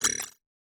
HiTech Click 4.wav